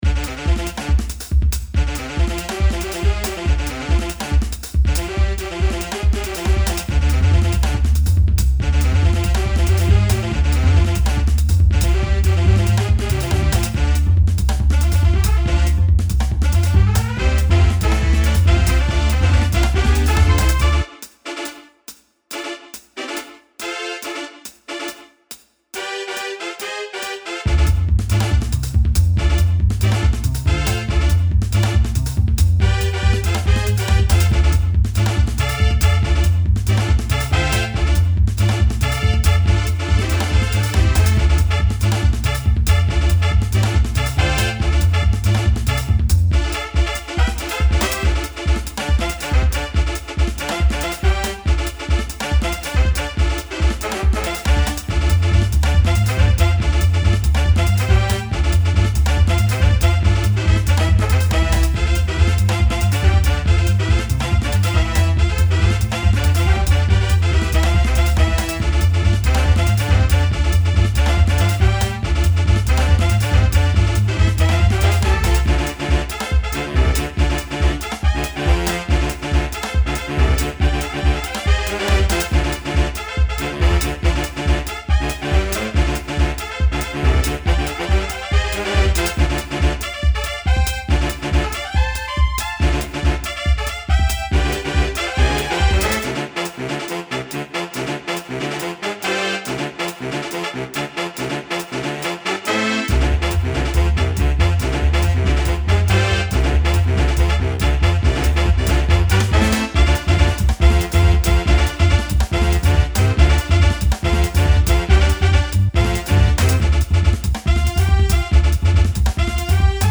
Game music
This piece has more of a jazz like feel and was written for a now abandoned video game project.